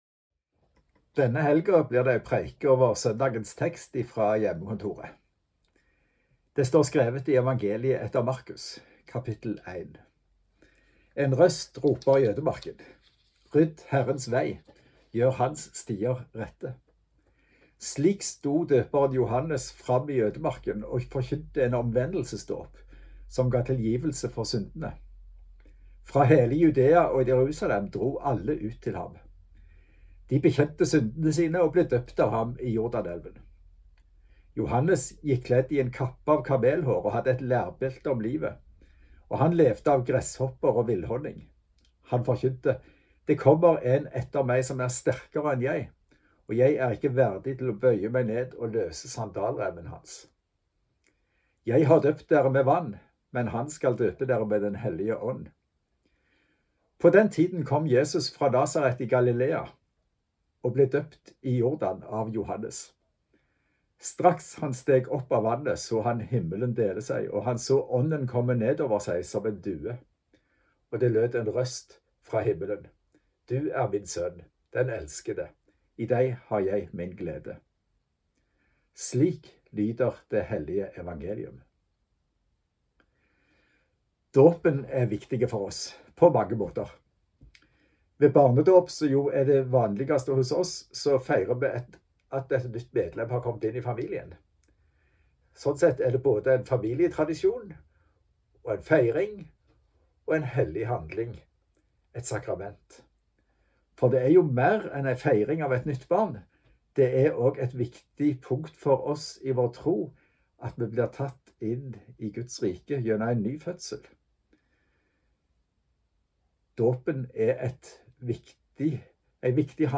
(samme som i filmen, men kun som lyd).